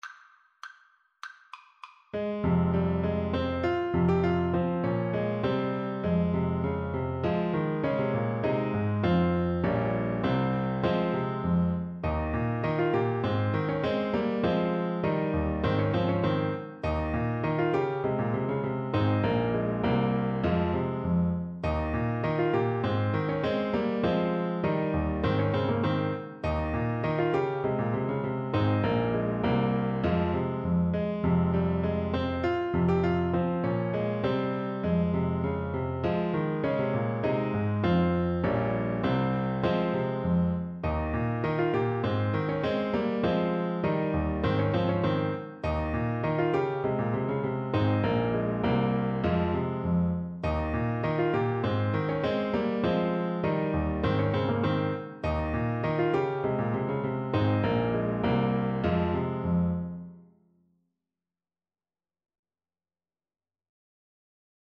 Traditional Trad. Prince Rupert's March from John Playford's 'Dancing Master' Alto Saxophone version
Play (or use space bar on your keyboard) Pause Music Playalong - Piano Accompaniment Playalong Band Accompaniment not yet available transpose reset tempo print settings full screen
Alto Saxophone
2/2 (View more 2/2 Music)
F minor (Sounding Pitch) D minor (Alto Saxophone in Eb) (View more F minor Music for Saxophone )
= 100 A
Traditional (View more Traditional Saxophone Music)